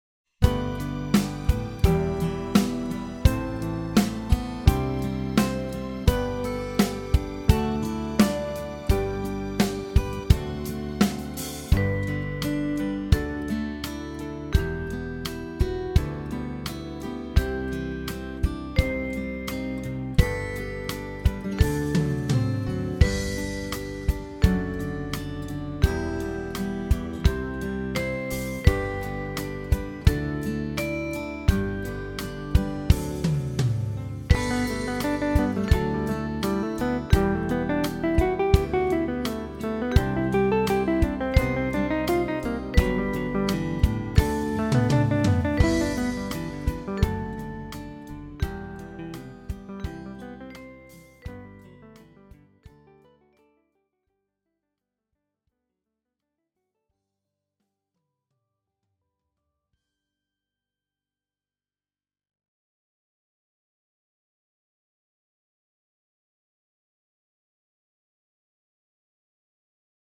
Maza dziesmiņa Play-along.
Spied šeit, lai paklausītos Demo ar melodiju